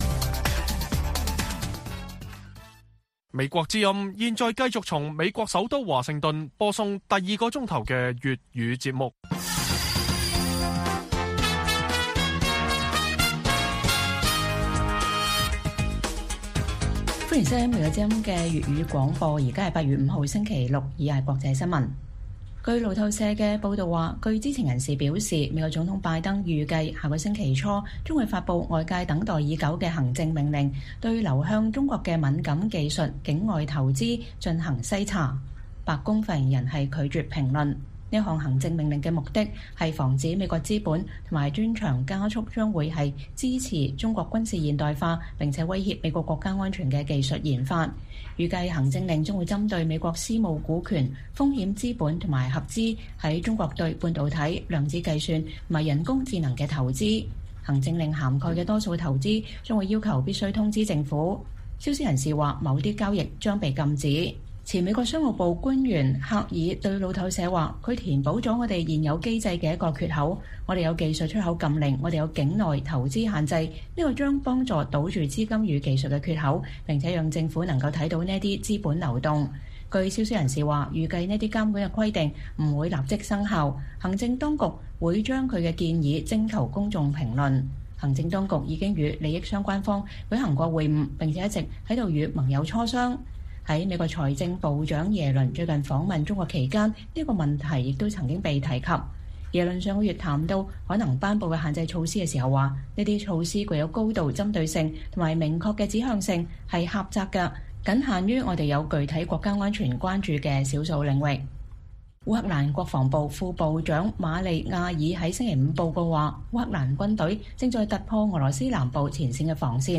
粵語新聞 晚上10-11點: 消息人士：拜登政府預計下周公布對中國技術投資的限制措施